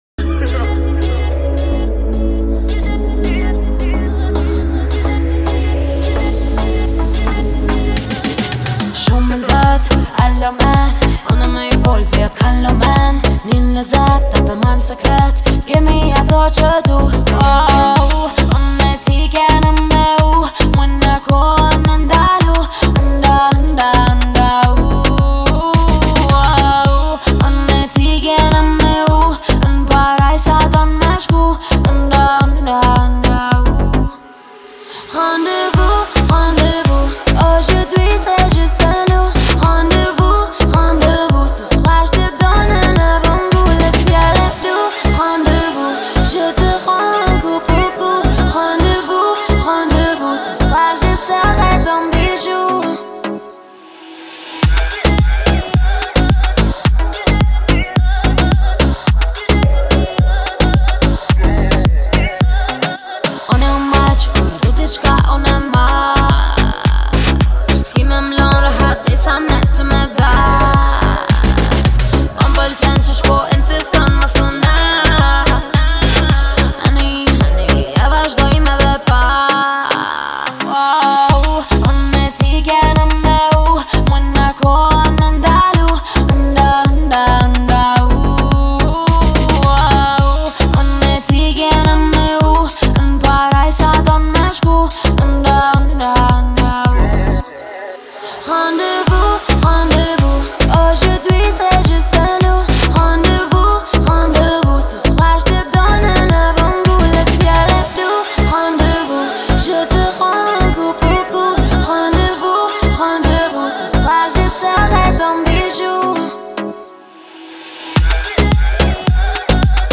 выделяется своим выразительным вокалом и харизмой